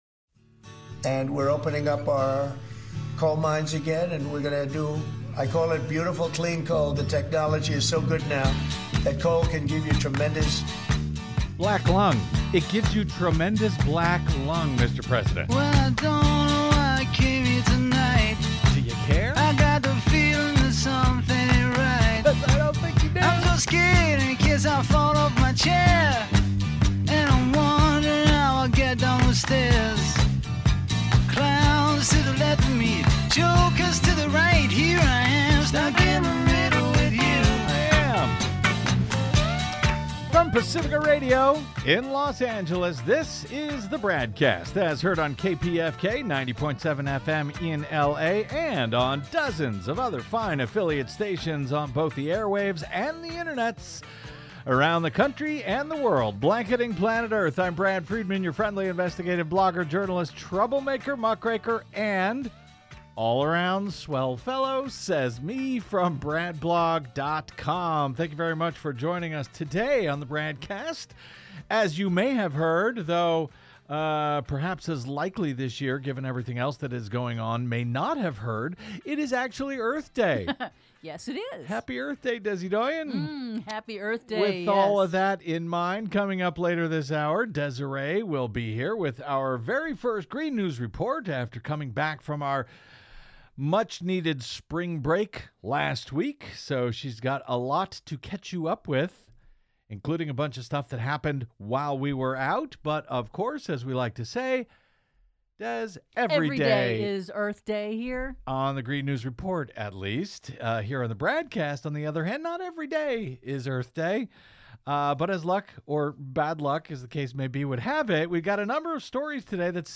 investigative interviews, analysis and commentary